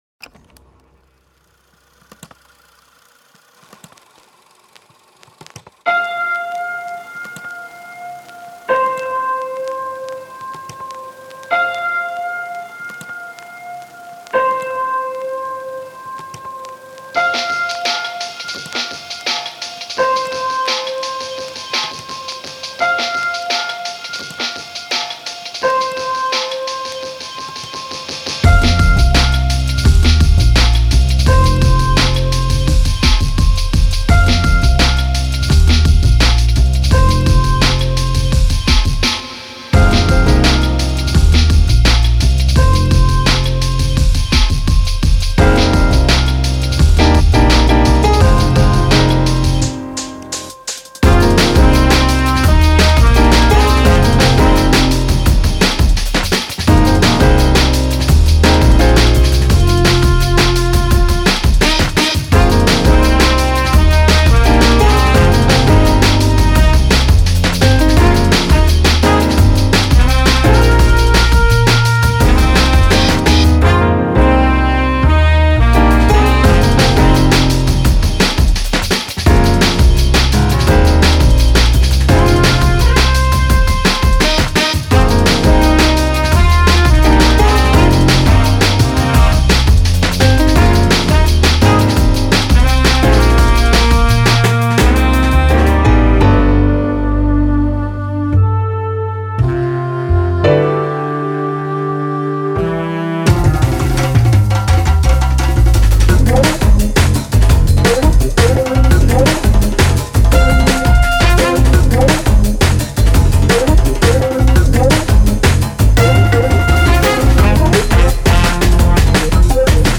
eine Idee aus Drum ’n’ Bass mit einer Hand voll Jazz.